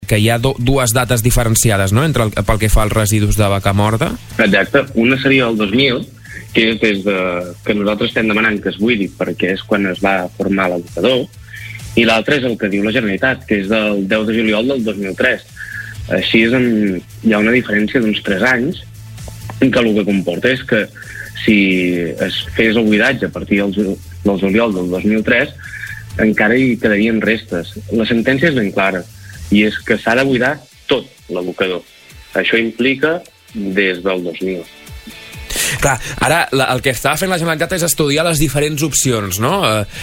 Supermatí - entrevistes
Al superat del 15 d’abril vam parlar amb Dani Encinas, alcalde de Cruïlles, Monells i Sant Sadurní de l’Heura sobre aquest tema.